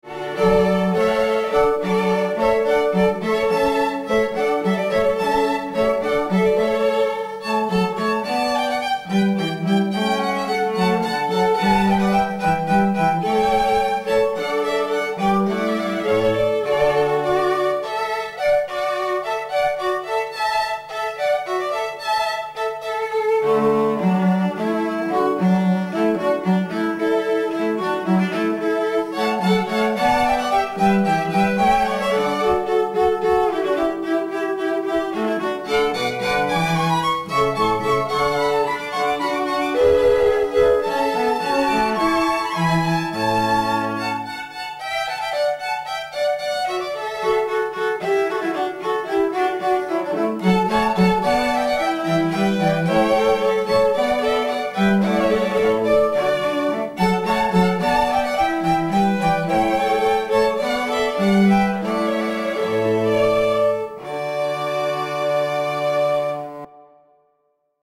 Comprised of four professional musicians, this highly-respected string quartet have been performing together for over 20 years, and the sophisticated sounds of their beautifully-harmonised strings create a magical, romantic atmosphere for wedding ceremonies or receptions.
• Professional string quartet